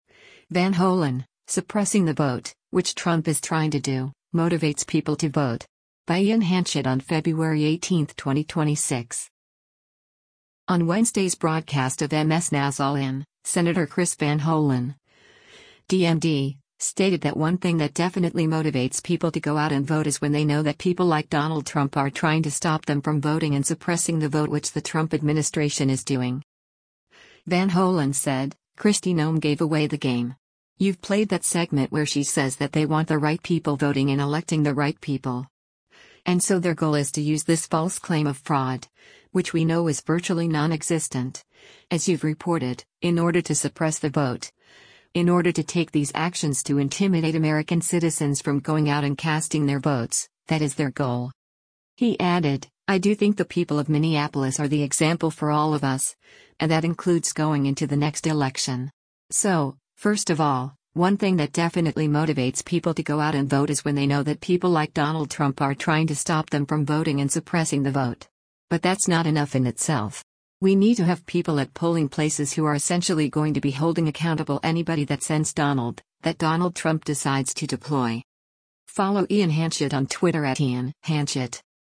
On Wednesday’s broadcast of MS NOW’s “All In,” Sen. Chris Van Hollen (D-MD) stated that “one thing that definitely motivates people to go out and vote is when they know that people like Donald Trump are trying to stop them from voting and suppressing the vote” which the Trump administration is doing.